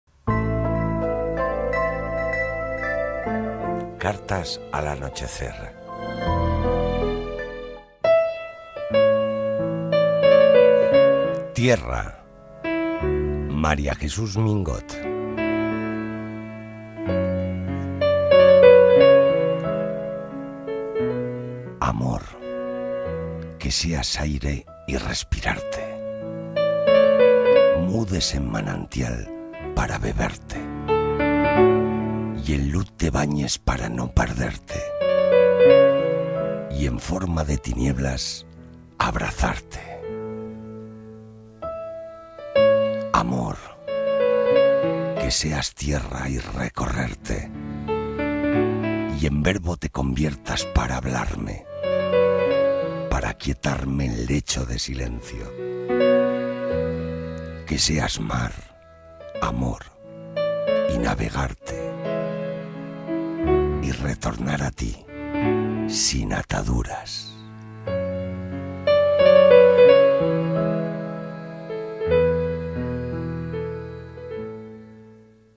Locutor profesional, más de 30 años de experiencia en radio, televisión , publicidad y márketing
kastilisch
Sprechprobe: eLearning (Muttersprache):